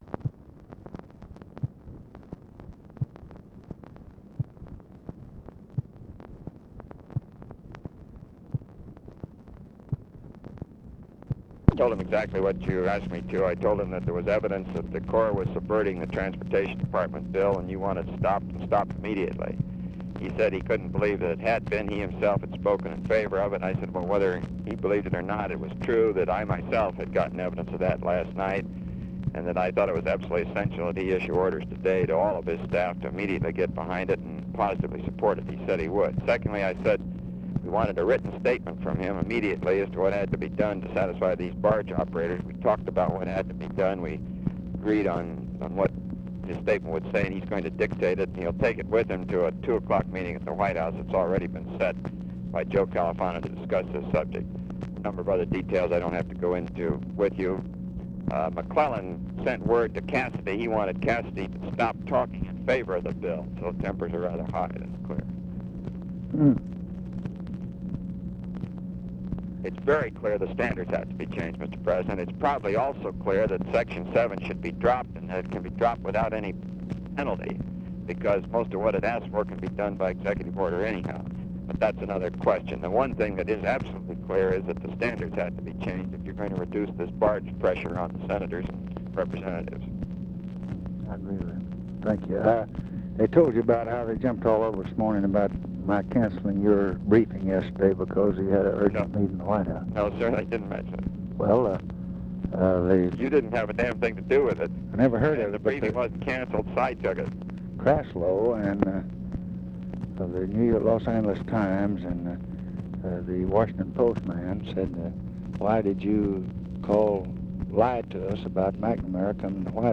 Conversation with ROBERT MCNAMARA, June 10, 1966
Secret White House Tapes